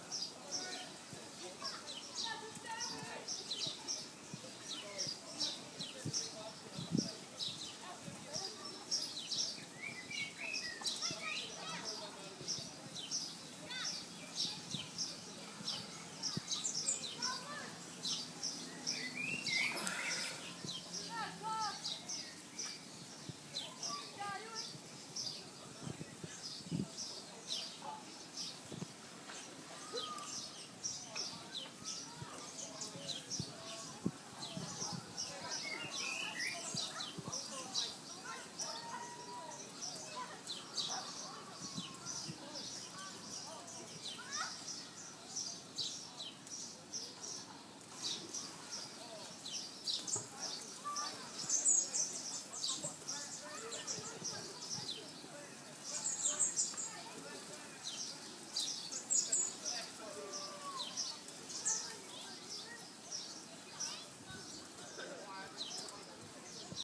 Listen above for the sounds of Raglan – Birds and Play
We heard songbirds out the window while we listened and watched kids laughing and playing on the beach.
Raglan-Birds-And-Play.m4a